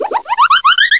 00716_Sound_flip.mp3